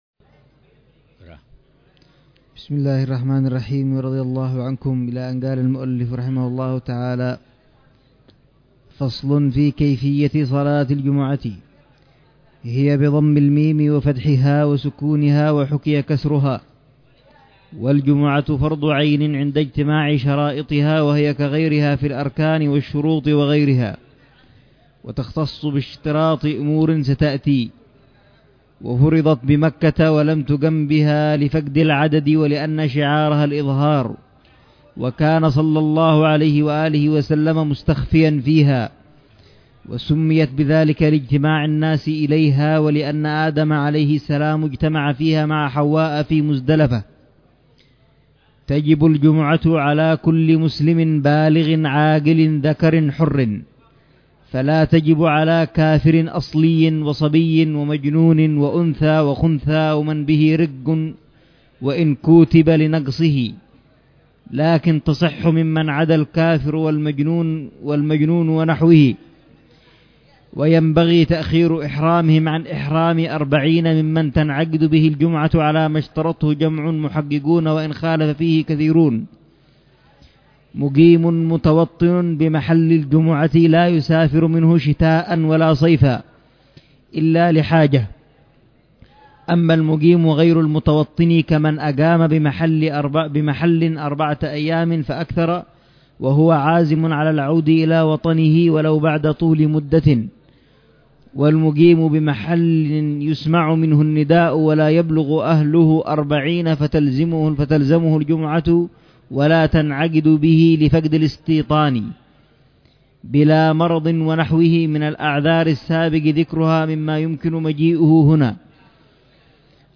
شرح الحبيب عمر بن حفيظ على كتاب كفاية الراغب شرح هداية الطالب إلى معرفة الواجب للإمام العلامة عبد الله بن الحسين بن عبد الله بلفقيه.